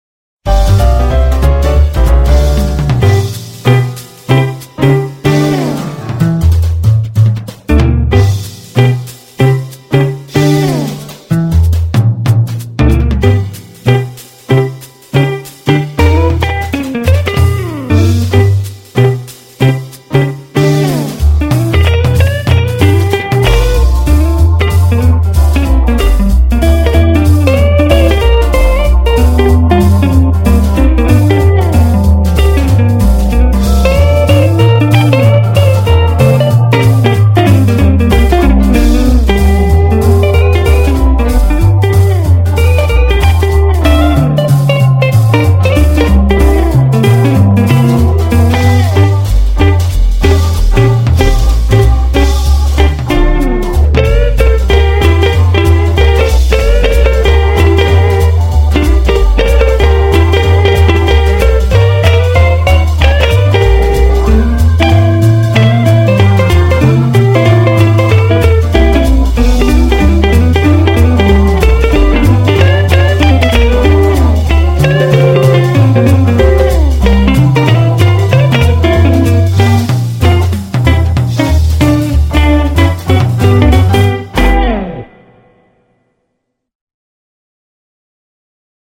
스윙 재즈 스타일인